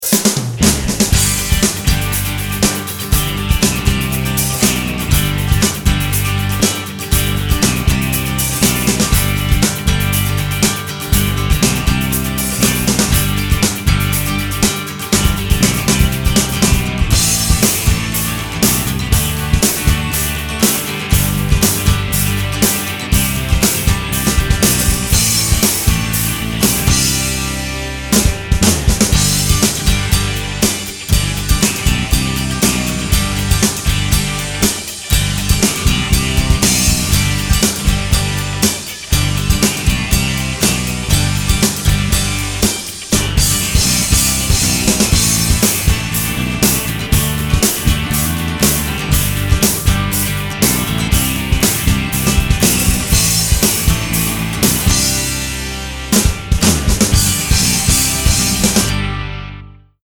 08.欢快的不.mp3